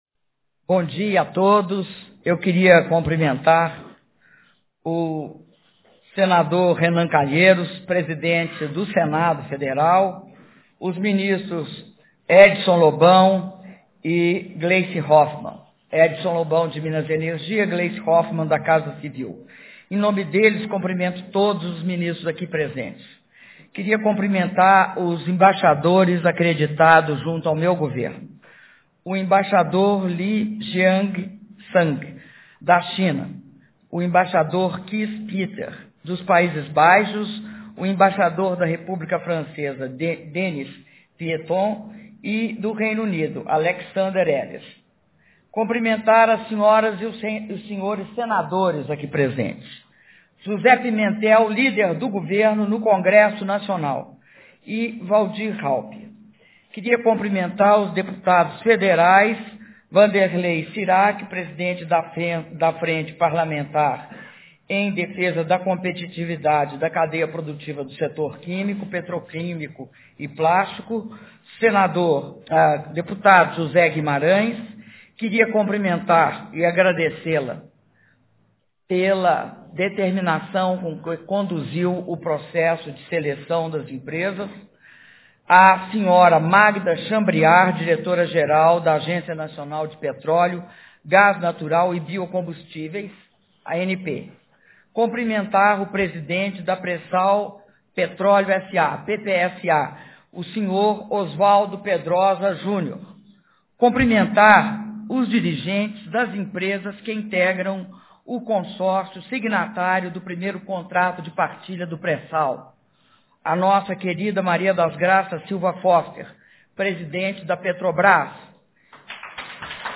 Áudio do discurso da Presidenta da República, Dilma Rousseff, na cerimônia de assinatura do Primeiro Contrato de Partilha do Pré-Sal - Brasília/DF